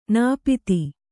♪ nāpiti